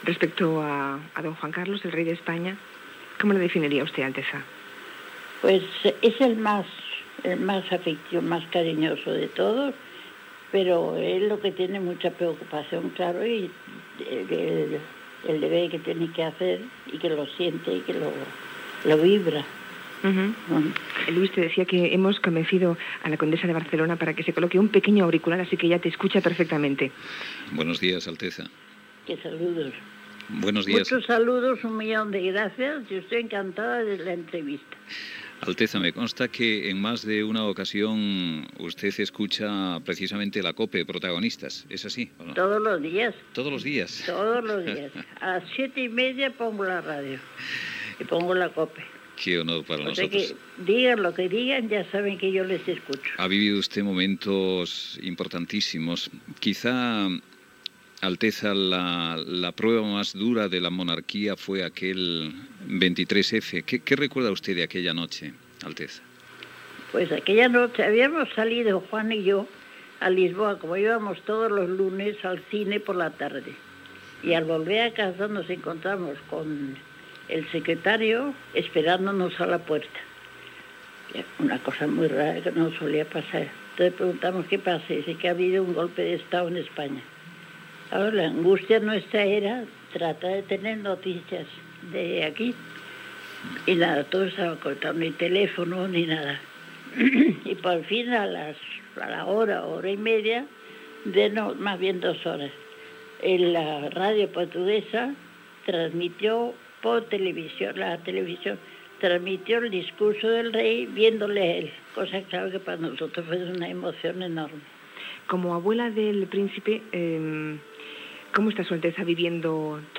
Fragment d'una entrevista de Julia Otero a la "condesa de Barcelona", María de las Mercedes Borbón y Orleans, feta a casa seva